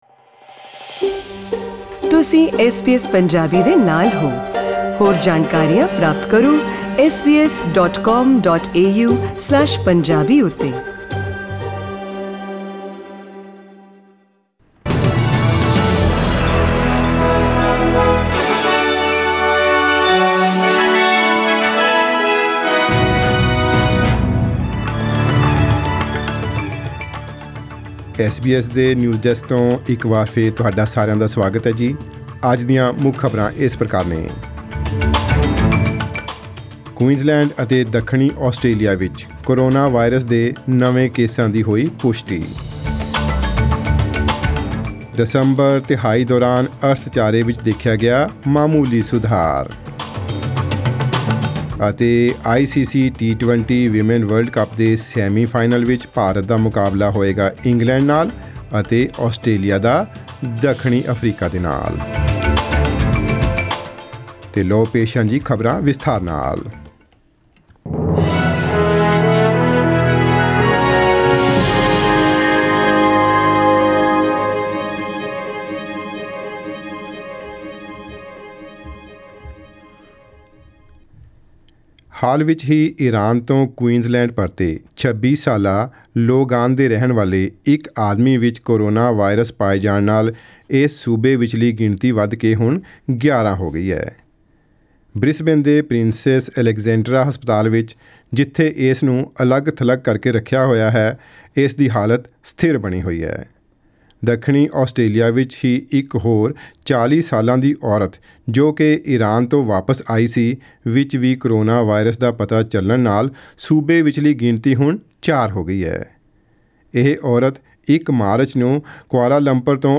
Previous news bulletins Australian News in Punjabi: 3 March 2020 Australian News in Punjabi: 2 March 2020 Australian News in Punjabi: 28 February 2020 Share